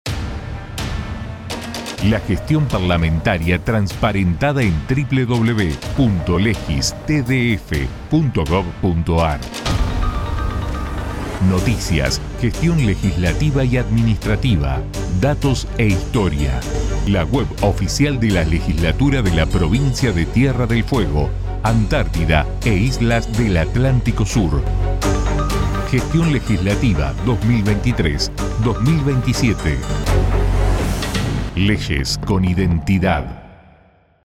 AVISOS PUBLICITARIOS DE RADIO (.MP3)